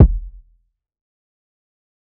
TC2 Kicks15.wav